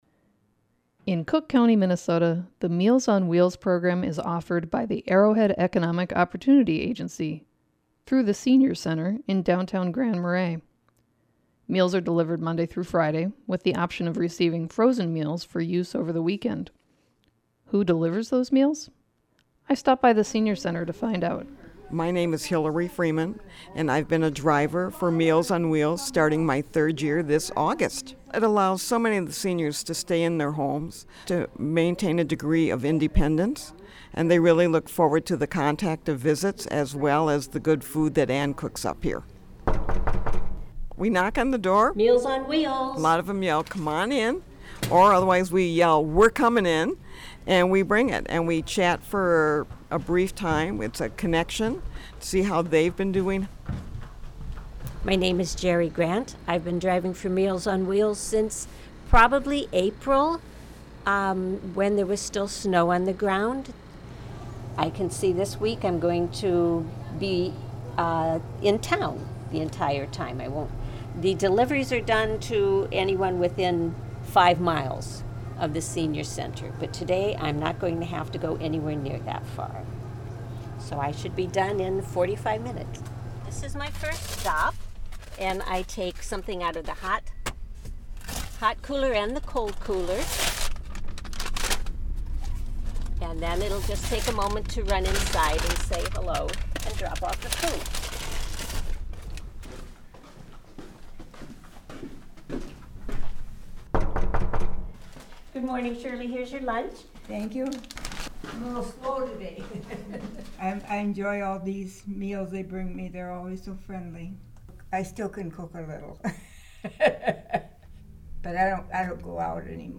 and has this report.